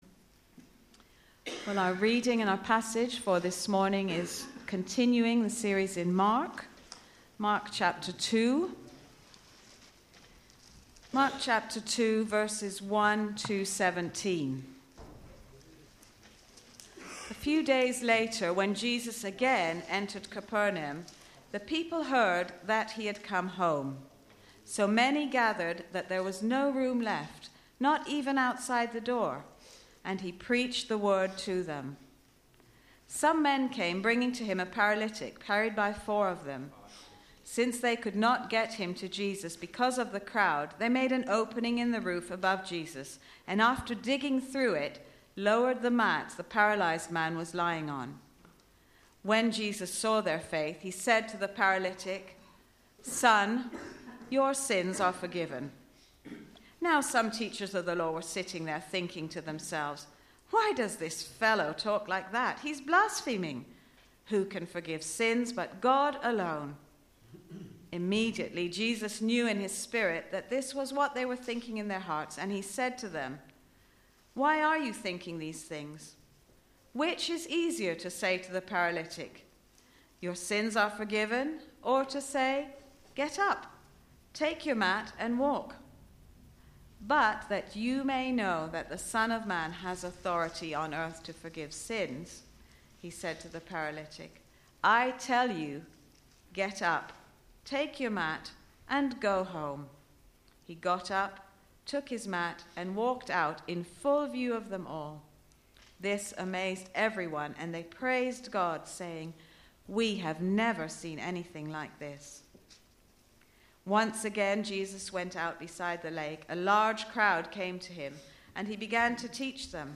Media for Sunday Service on Sun 16th Jun 2013 11:00
Sermon